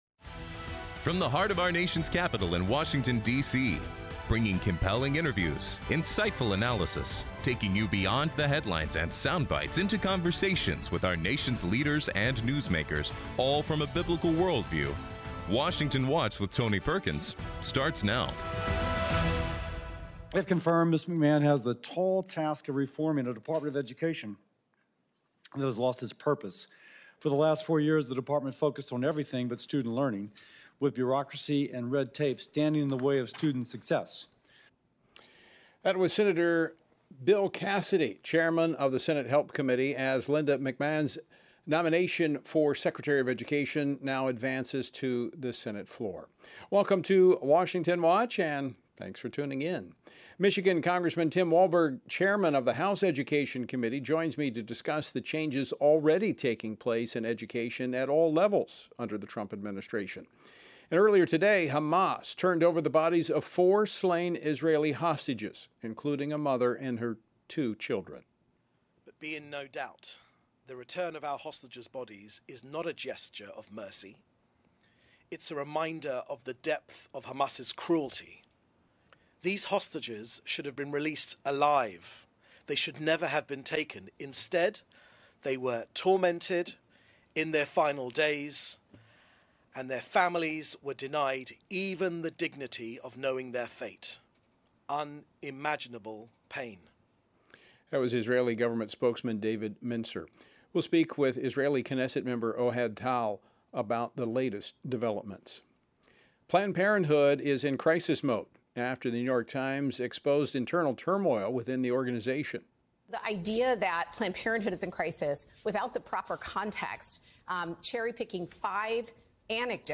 On today’s program: Tim Walberg, U.S. Representative for Michigan’s 5th District and Chairman of the House Education and Workforce Committee, discusses the news of the day and new developments at the Department of Education. Ohad Tal, Member of the Israeli Knesset, gives an update on the recent hostage release and details his bill protecting the historic sovereignty of Judea and Samaria.